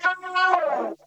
VOC FALLOFF1.wav